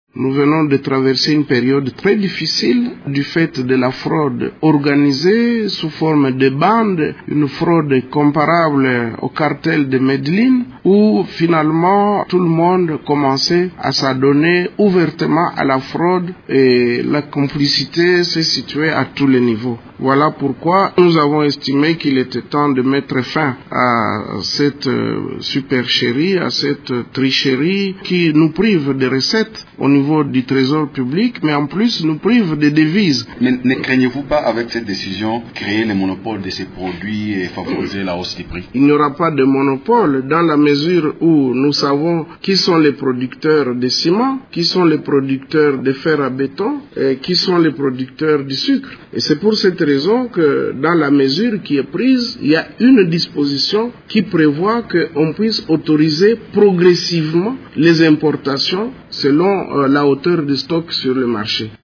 Vous pouvez écouter le ministre Bahati ici :